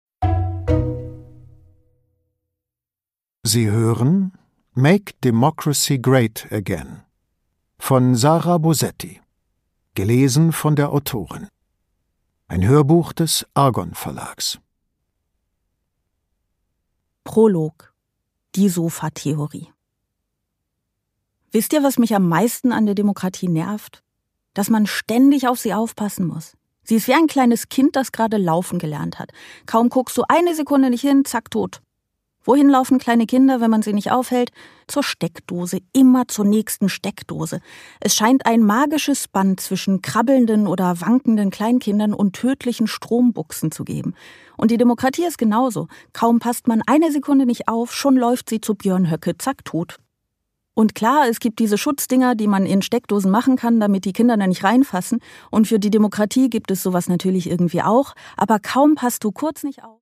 Produkttyp: Hörbuch-Download
Gelesen von: Sarah Bosetti